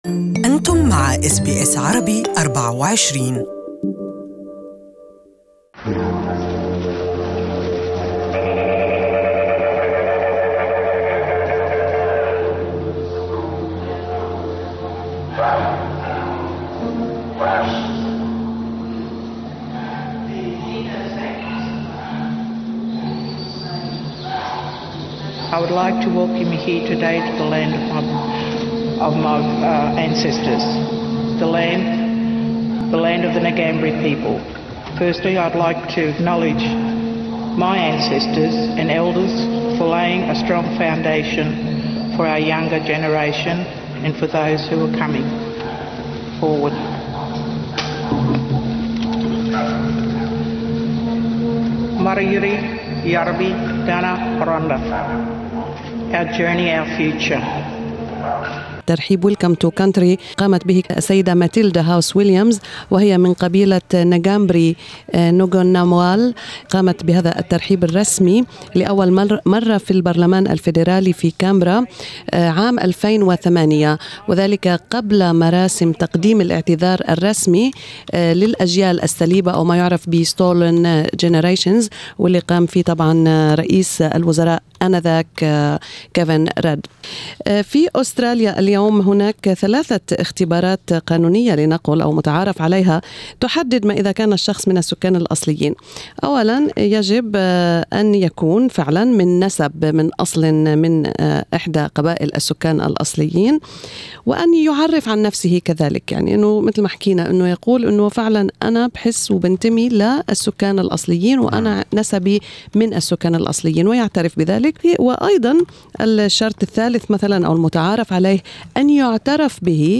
استمعوا إلى مزيد من المعلومات في التقرير الصوتي أعلاه.